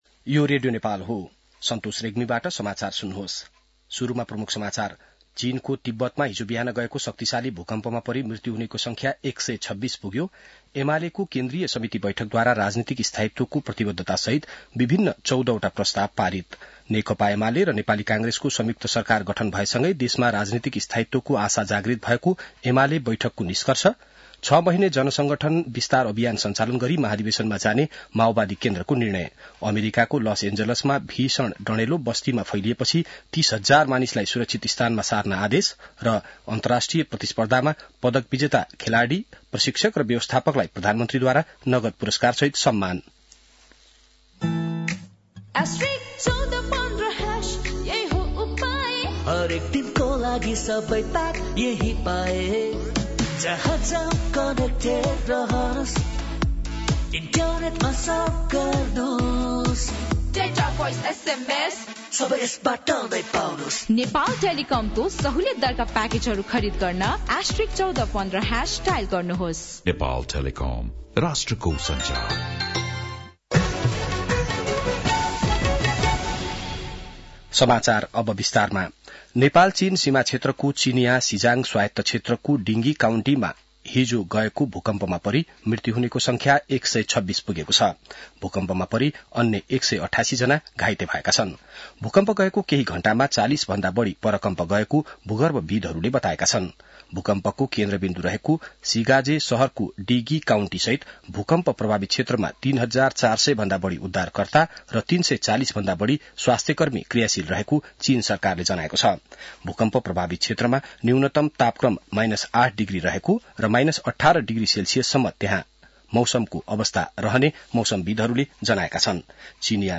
बिहान ७ बजेको नेपाली समाचार : २५ पुष , २०८१